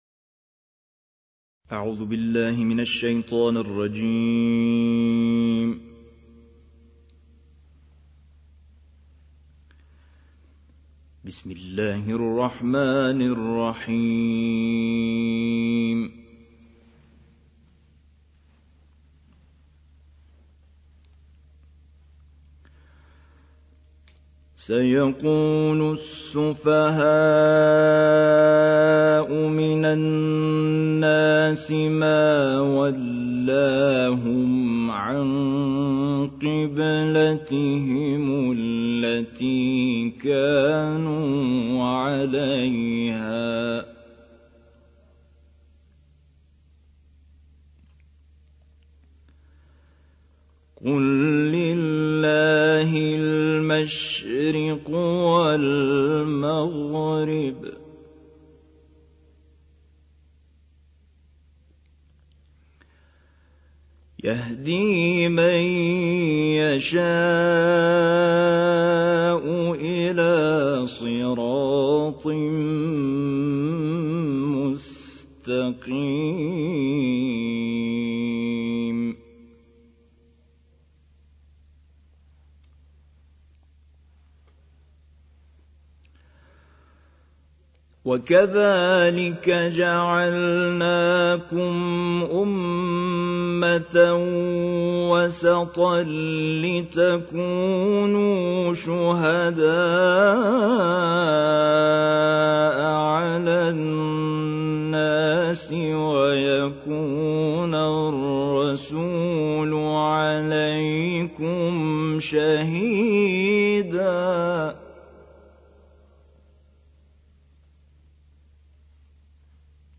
دانلود قرائت سوره بقره آیات 142 تا 157